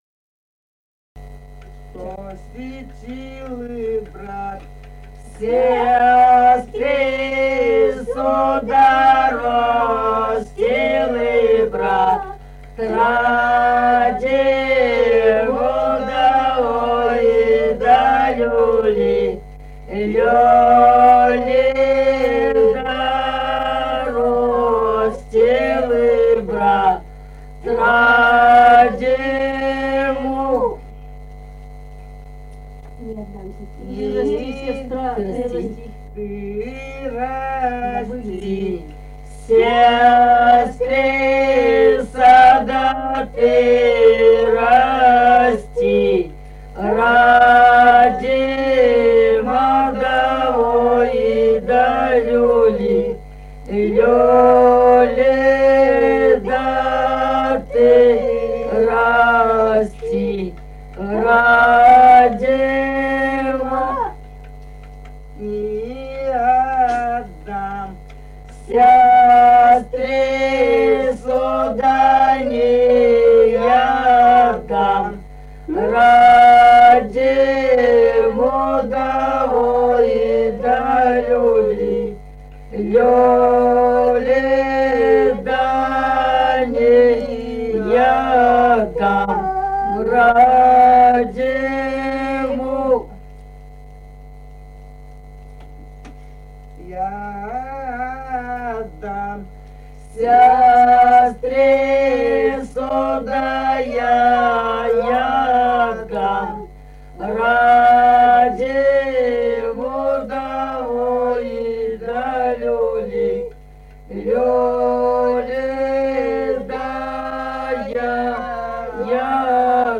| filedescription = «Ро́стил брат сестрицу», «лужошная».
Республика Казахстан, Восточно-Казахстанская обл., Катон-Карагайский р-н, с. Фыкалка, июль 1978.
Прим.: с тонким голосом.